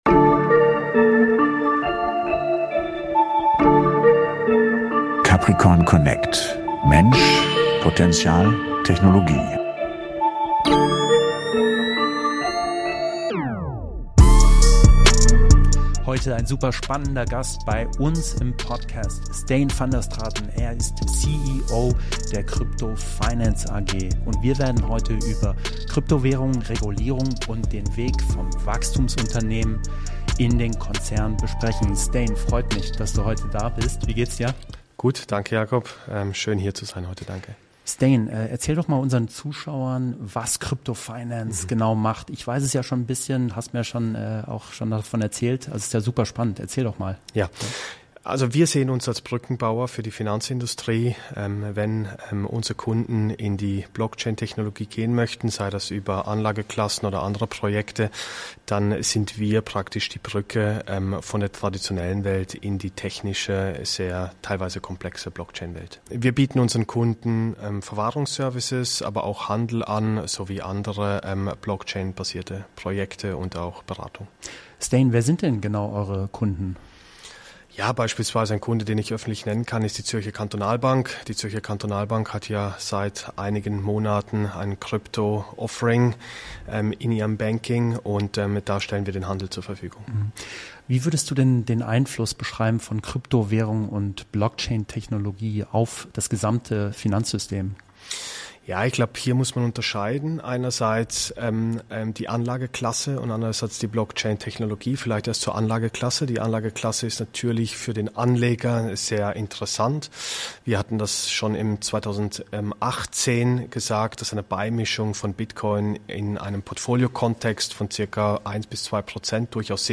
Ein Gespräch über Technologie, Vertrauen, geopolitische Dynamik und den realen Wandel in den Finanzmärkten.